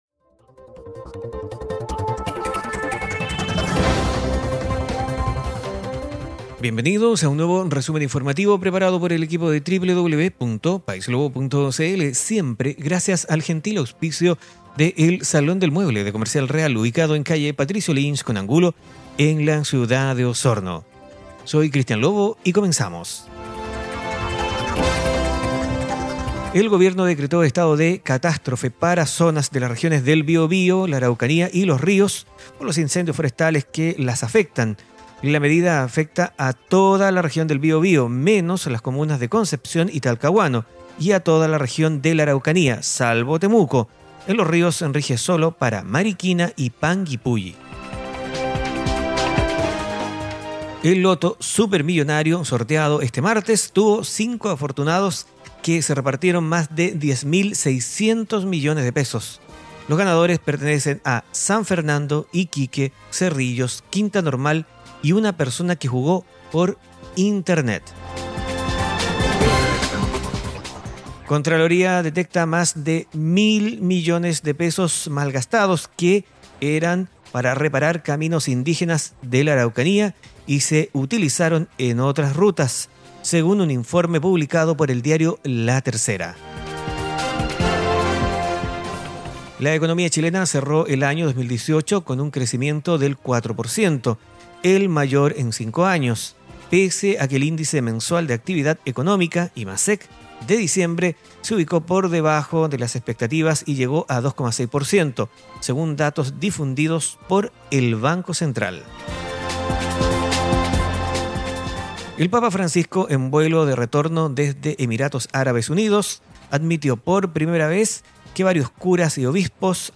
Noticias en pocos minutos.